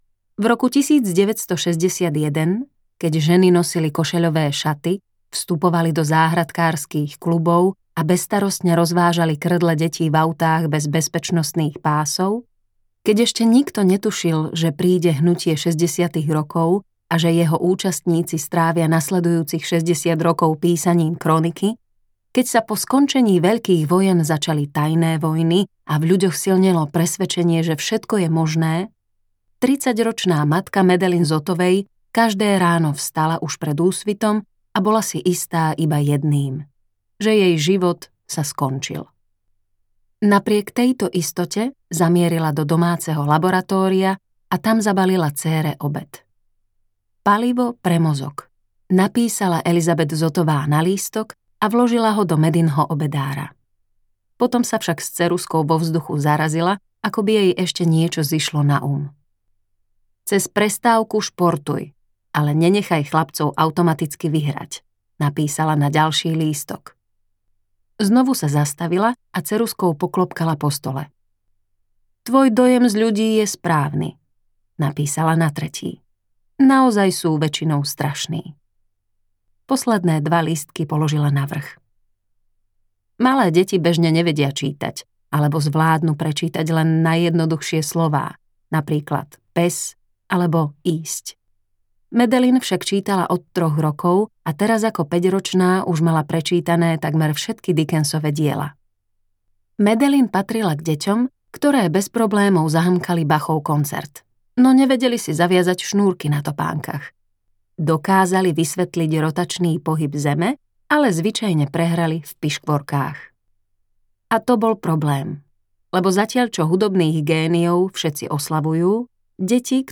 Hodiny chémie audiokniha
Ukázka z knihy